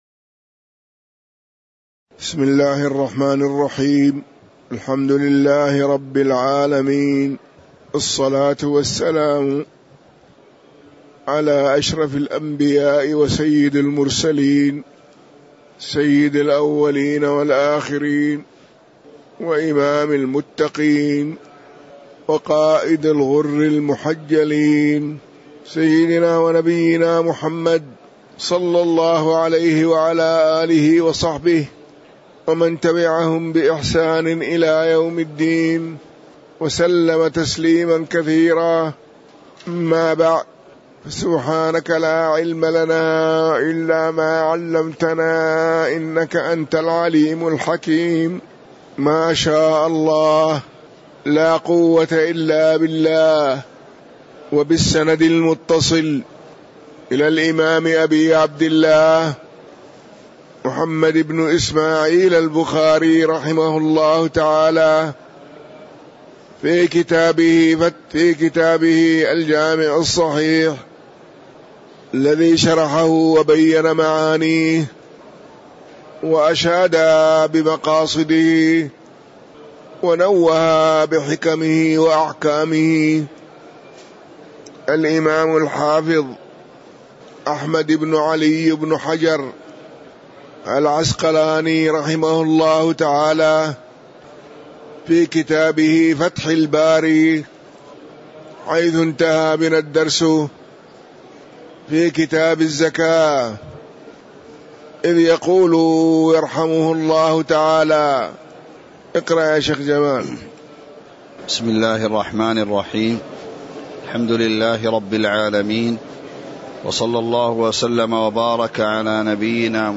تاريخ النشر ١ ربيع الثاني ١٤٤٤ هـ المكان: المسجد النبوي الشيخ